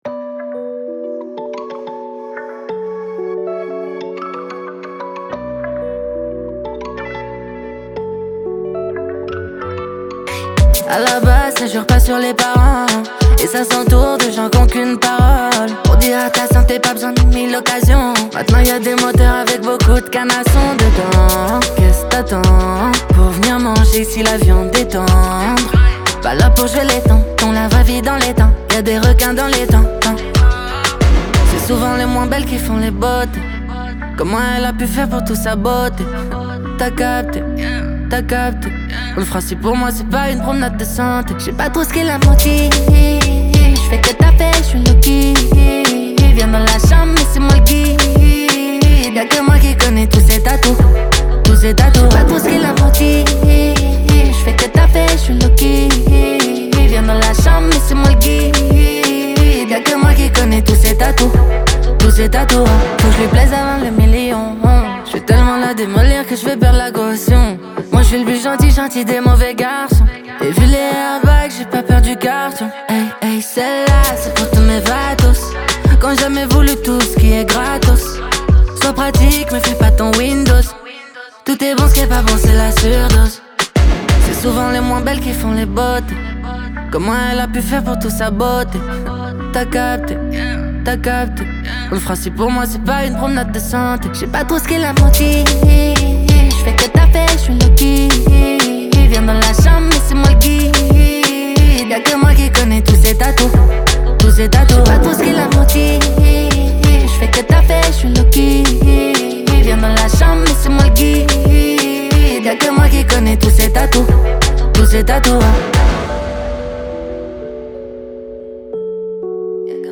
french rap, pop urbaine Télécharger